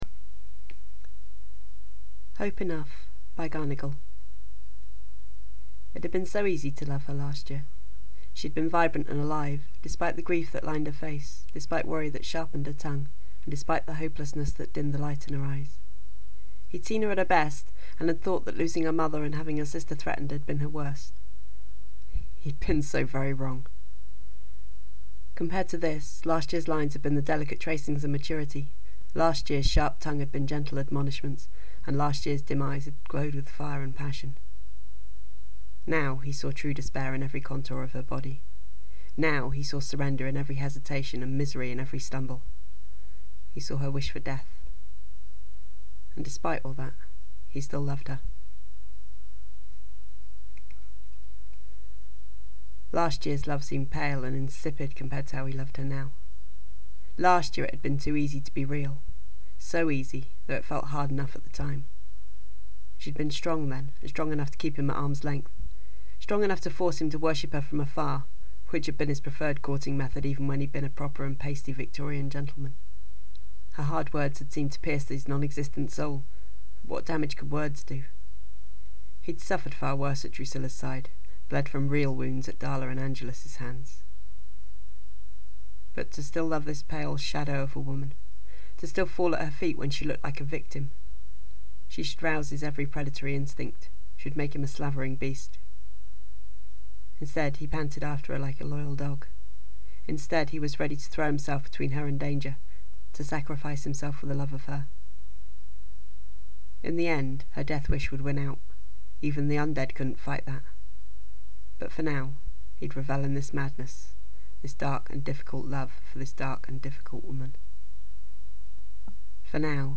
Just in case anyone doesn’t know: Podfic – FanFiction that is read aloud and then made available for download.